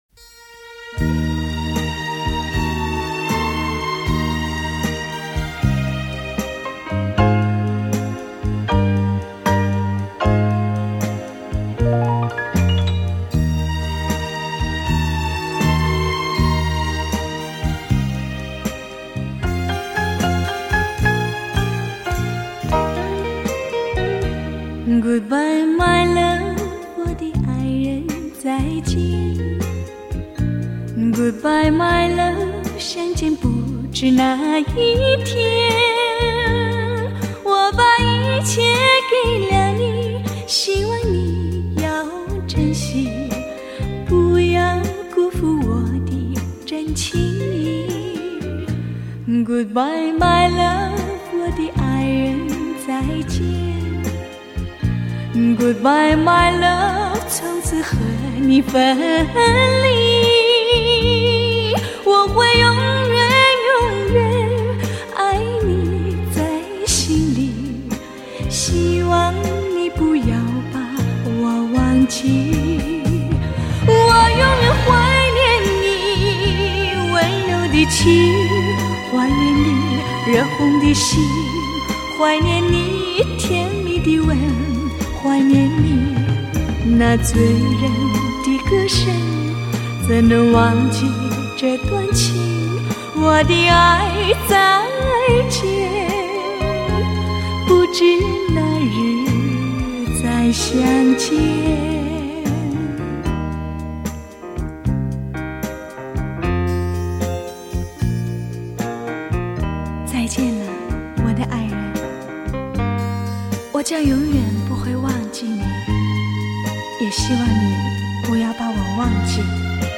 以当今至高规格音效处理
DXD重新编制